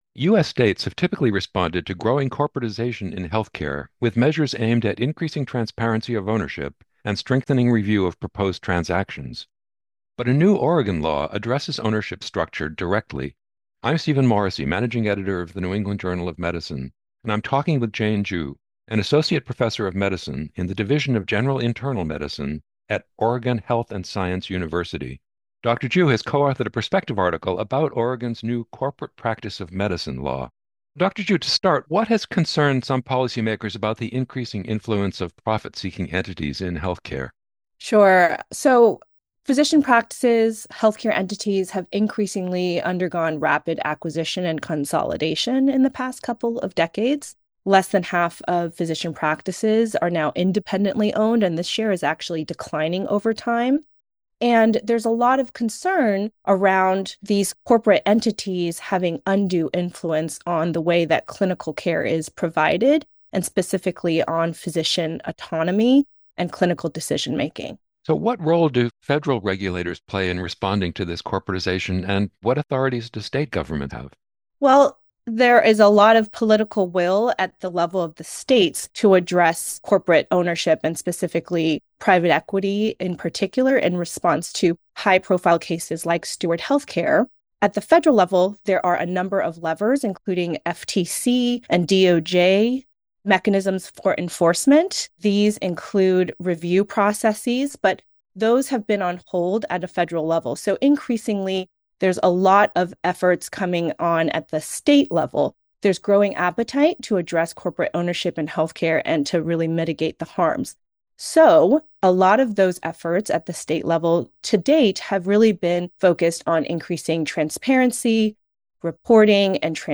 1 NEJM Interview